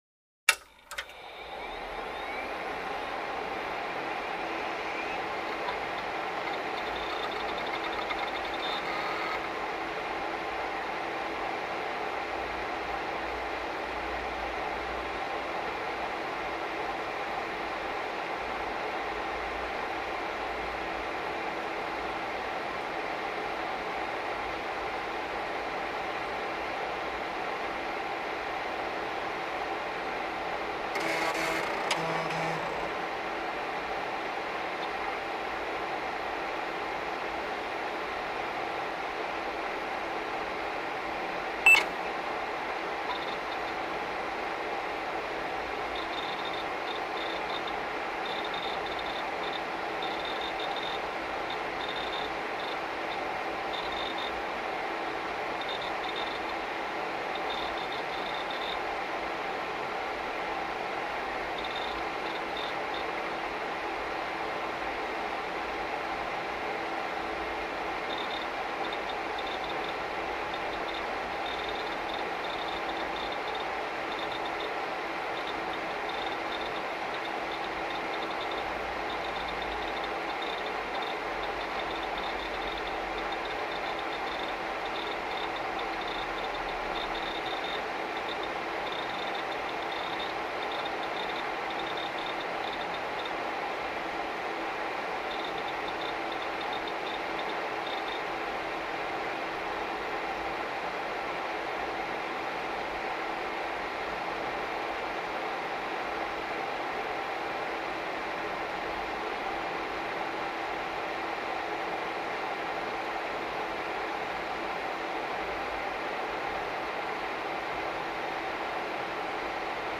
PC On | Sneak On The Lot
PC; On / Boot / Steady / Off; Desktop Computer; Power On / Hard Drive And Fan Spin Up / Beep / Hard Drive Access / Steady Fan Idle / Power Off / Hard Drive And Fan Spin Down, Close Perspective.